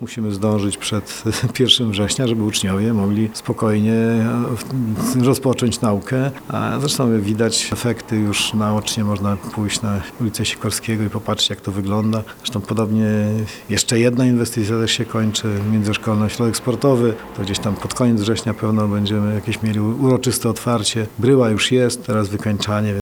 Zbliżają się terminy zakończenia termomodernizacji dwóch szkół: Zespołu Szkół nr 2 oraz nr 5. Jak mówi Marek Chojnowski, starosta powiatu ełckiego, przed pierwszym dzwonkiem wykonawcy mają zakończyć prace.
starosta-szkoły.mp3